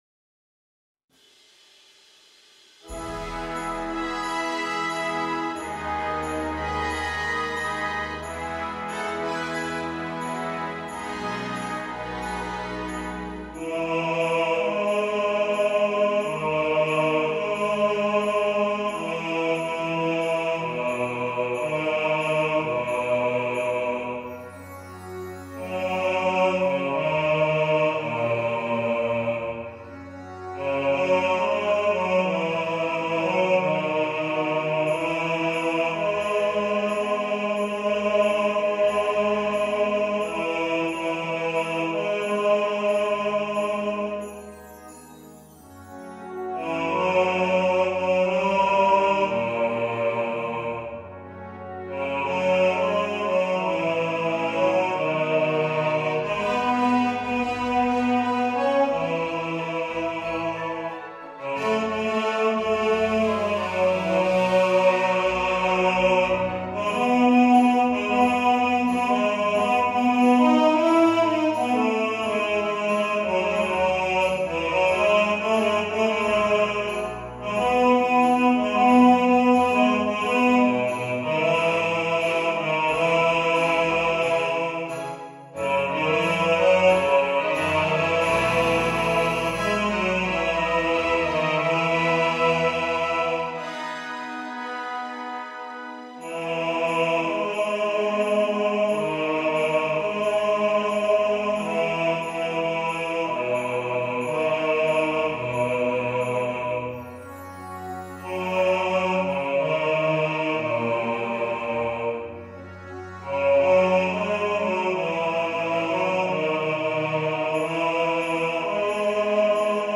Believe (Polar Express) – Bass | Ipswich Hospital Community Choir